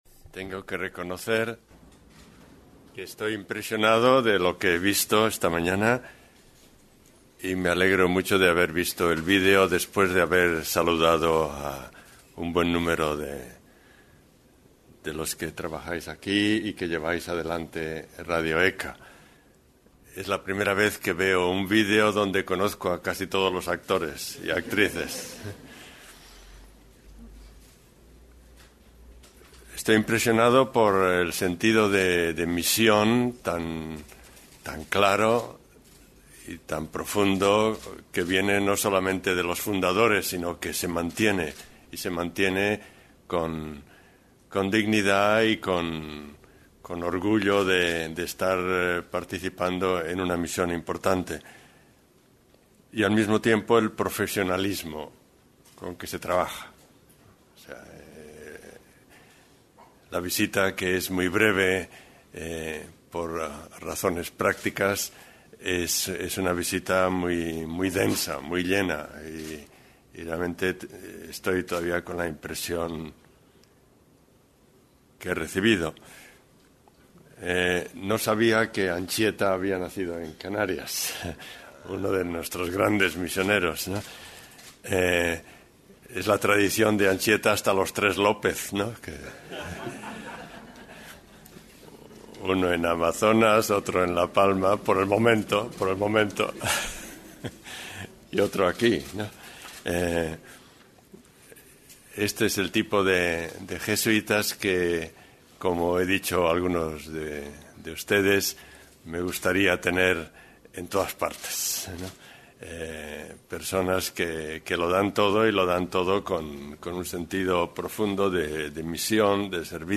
Escucha la entrevista al P. Adolfo Nicolás, S.J. durante su visita 🛜